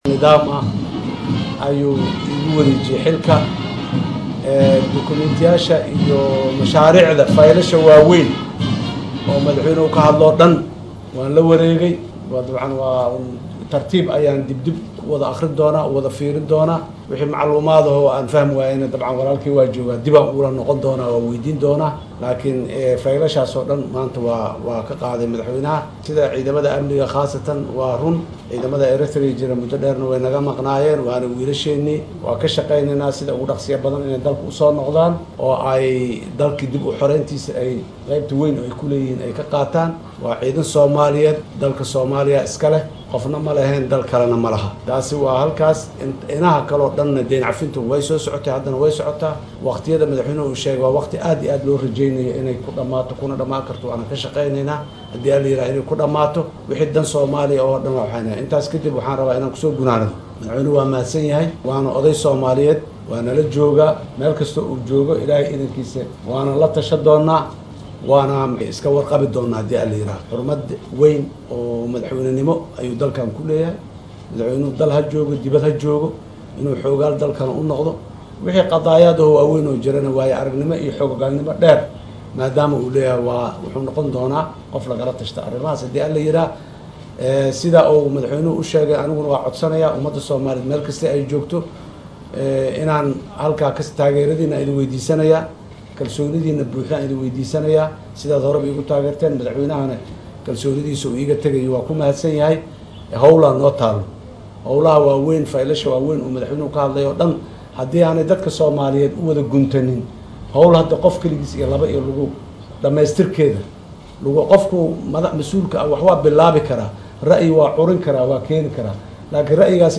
Madaxweynaha cusub ee dalka Soomaaliya Xasan Sheekh Maxamuud ee maanta xilka si rasmi ah ula wareegay ayaa sheegay inay ka shaqeyn doonaan sidii ciidamadii Eritrea la geeyay wadanka sida uga dhakhsiyaha badan dib loogu soo celin lahaa. Mar uu arrimahan ka hadlay ayuu yiri Xasan Sheekh Maxamuud